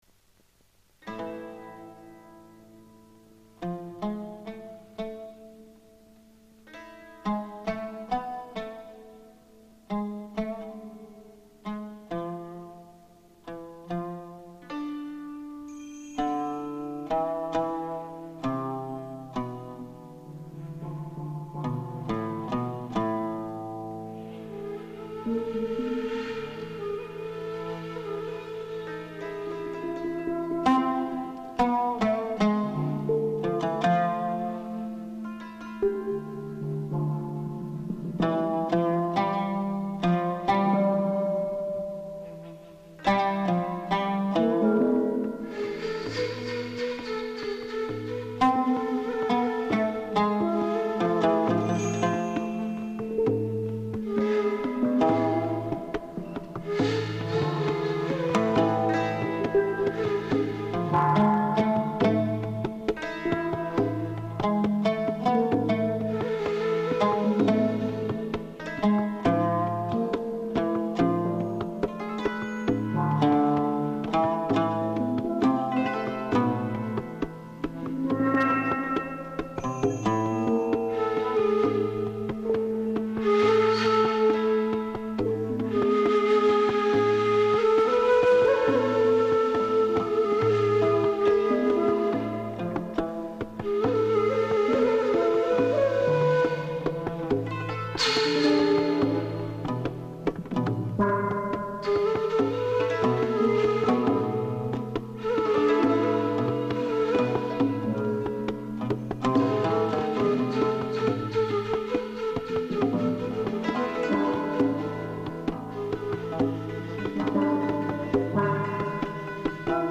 【試聴】 ：オープニングタイトルで流れる音楽を演奏します。
歪んだ笛の音は、